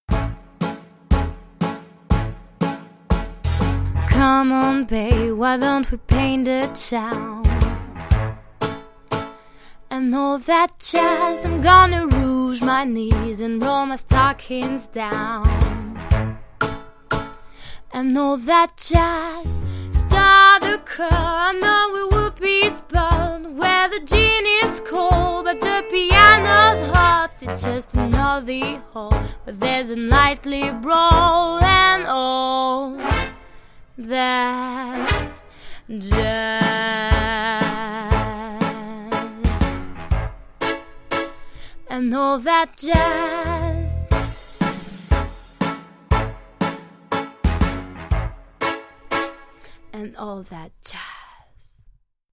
- Mezzo-soprano